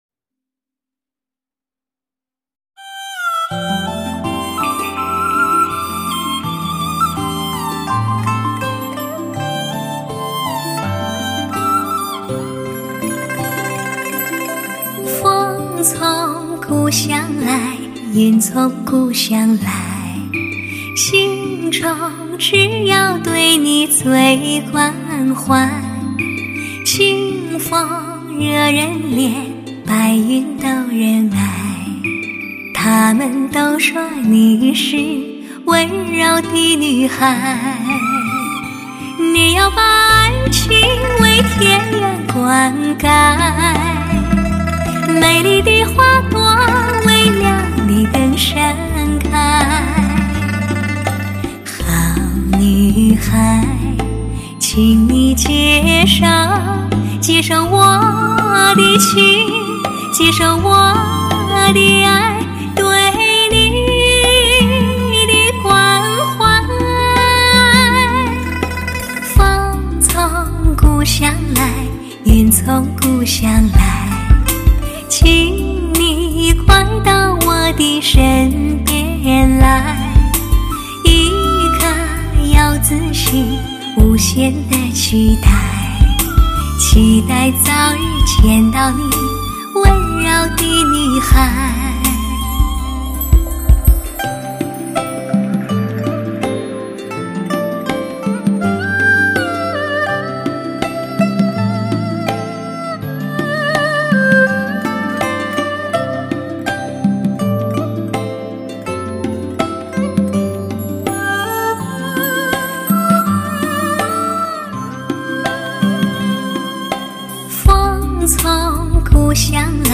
豆蔻年华里的甜美女声，纯洁无暇，唯美亲切，
犹如三月里的春风，清新袭人……